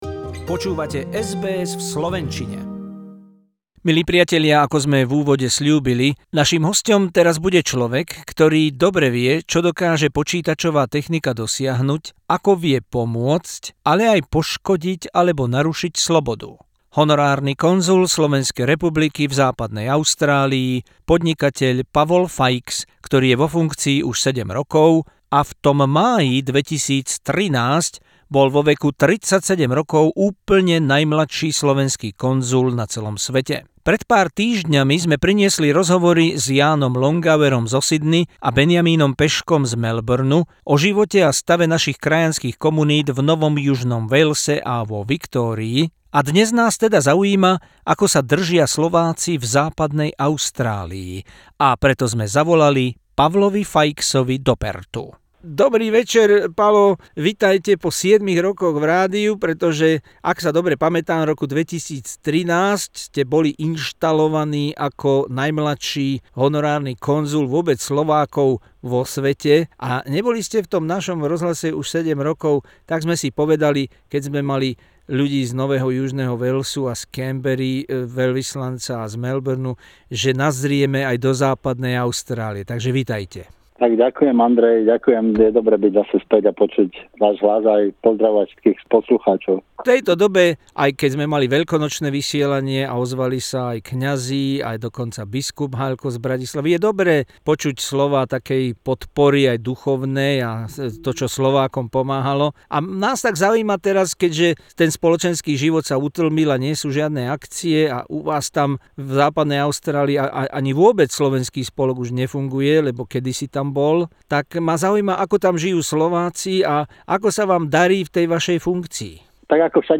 Exclusive SBS Slovak interview with the Honorary Consul of Slovak Republic in WA Pavol Faix from Perth.